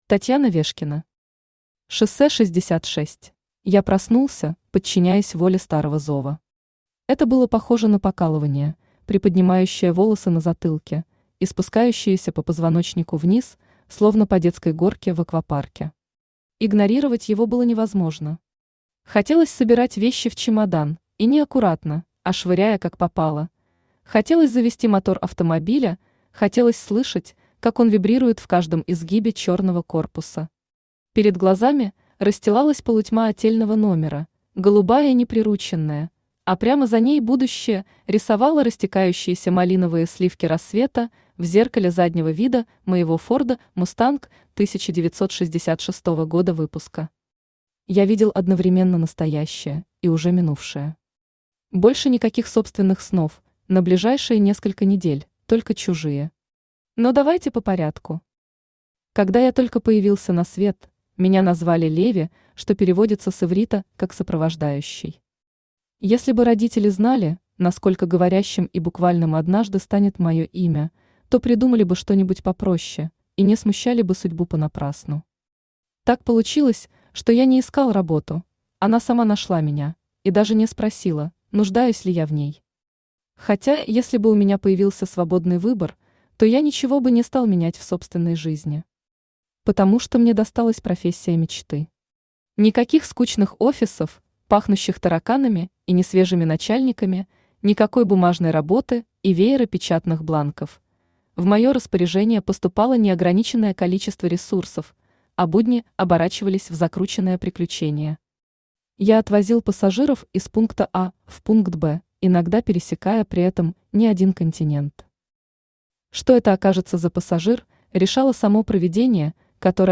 Аудиокнига Шоссе 66 | Библиотека аудиокниг
Aудиокнига Шоссе 66 Автор Татьяна Вешкина Читает аудиокнигу Авточтец ЛитРес.